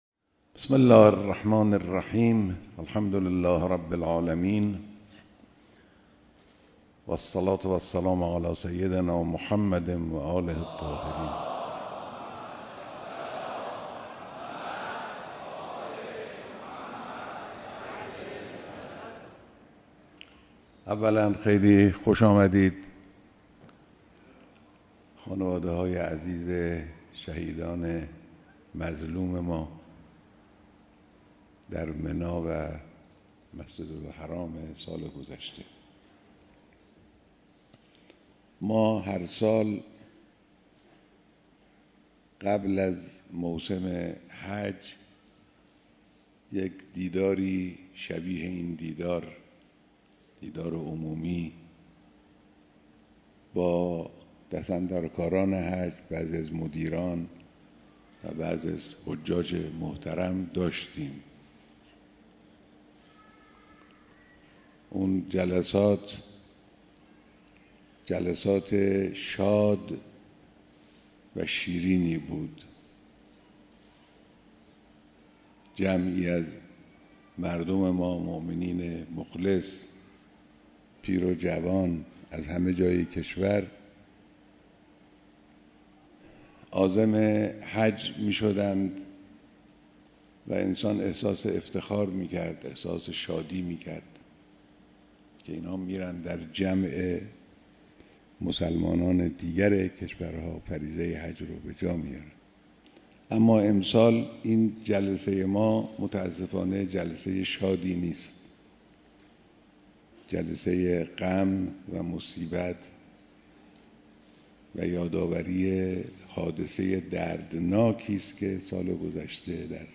بیانات در دیدار خانواده های شهدای منا